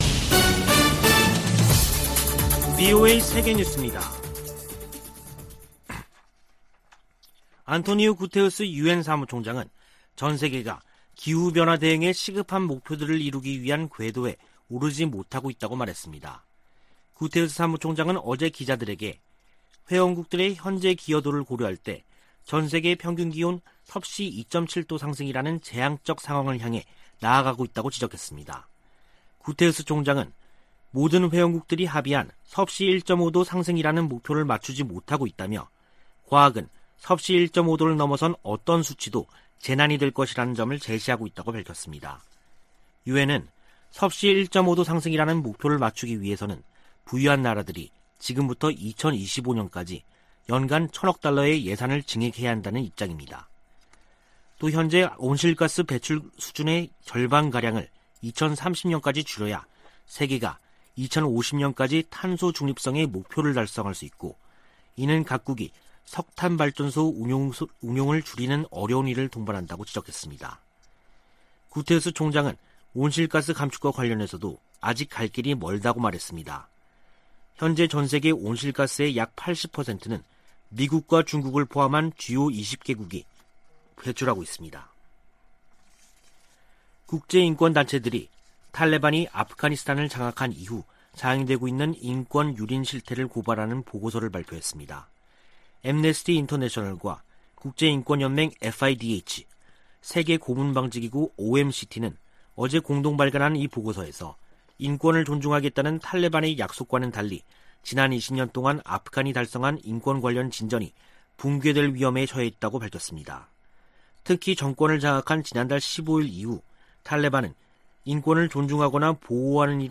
VOA 한국어 간판 뉴스 프로그램 '뉴스 투데이', 2021년 9월 21일 3부 방송입니다. 북한이 우라늄 농축 등 핵 프로그램에 전력을 기울이고 있다고 국제원자력기구 사무총장이 밝혔습니다. 미 국무부는 북한의 최근 핵 관련 움직임과 탄도미사일 발사가 유엔 안보리 결의 위반이며 한국과 일본 등에 위협이라고 지적했습니다. 미 국방부는 한국보다 더 강력한 동맹국은 없다며, 한반도 위협에 군사적 대비태세를 갖추고 있다고 강조했습니다.